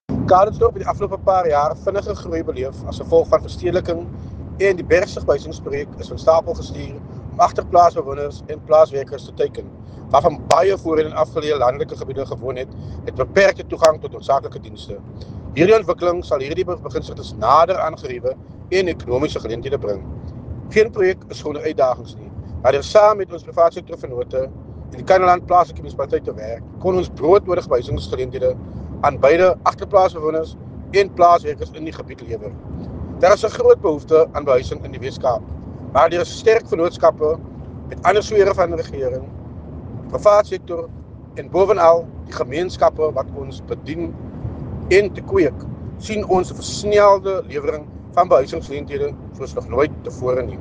t-simmers-calitzdorp-housing-handover-afr.mp3